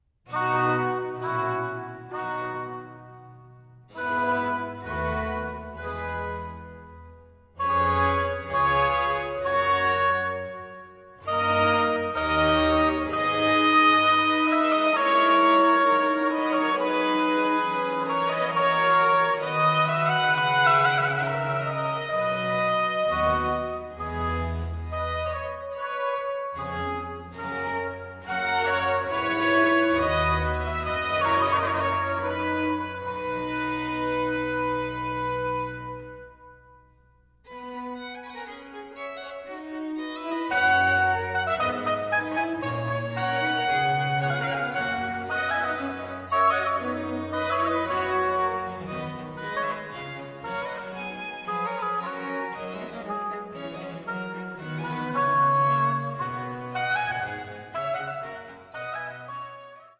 "Beautifully recorded."